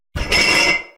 BottleAddToInventory.ogg